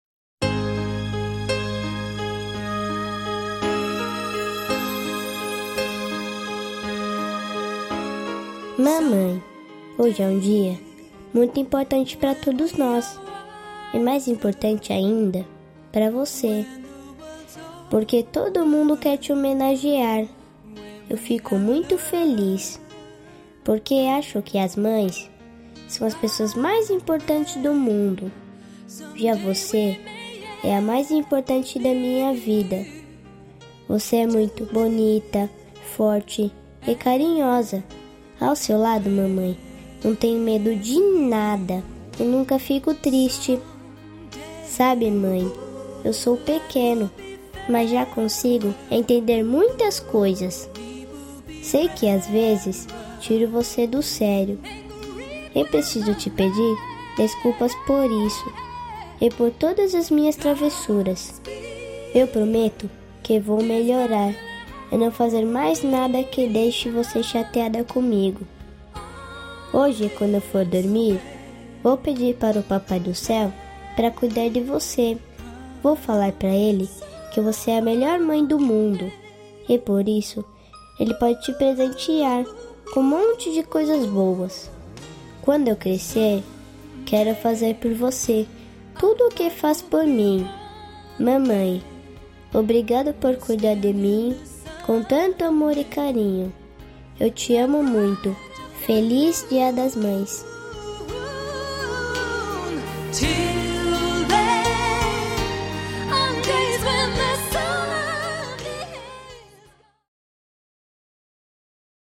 Para Mãe (Filha criança Envia)
Voz Menino
41-Feliz-Dia-das-Maes-Filha-Crianca-M.mp3